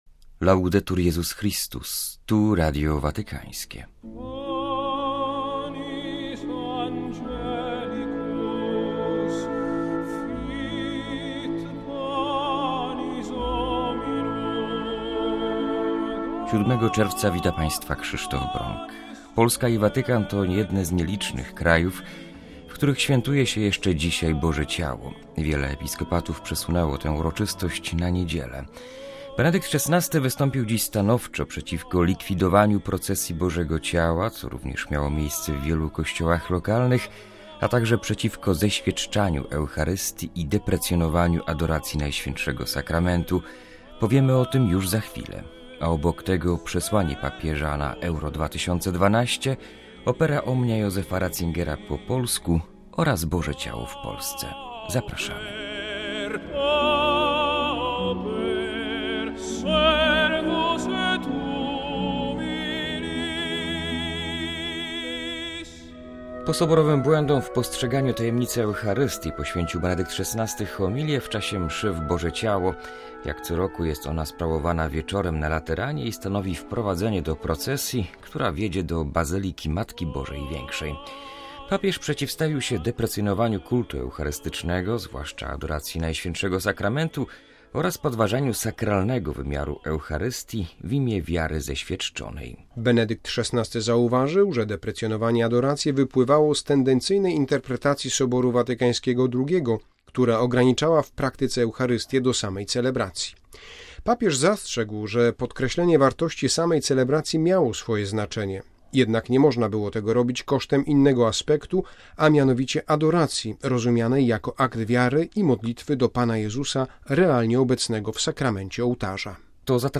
Home Archivio 2012-06-07 19:58:43 Magazyn w czwartek W programie: - papieska homilia na Boże Ciało; - Benedykt XVI na Euro 2012; - Opera Omnia Josepha Ratzingera po polsku – rozmowa z wydawcami; - Boże Ciało w Polsce.